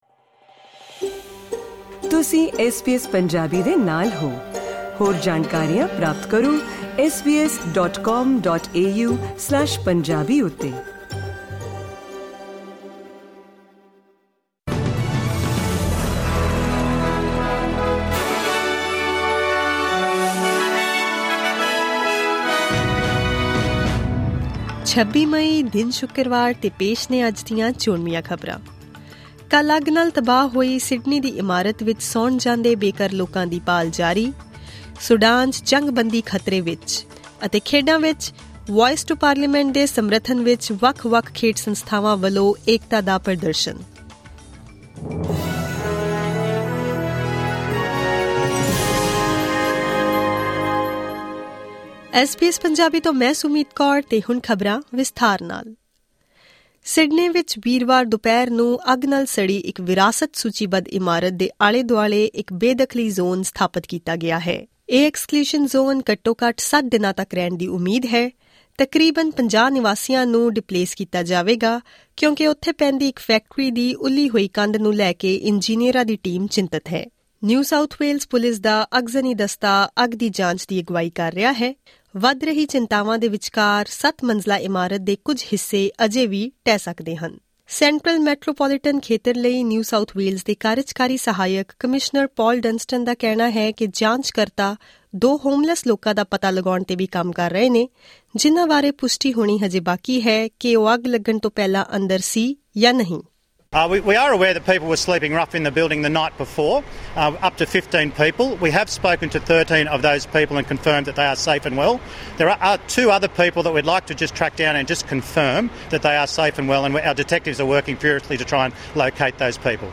SBS Punjabi Australia News: Friday 26 May 2023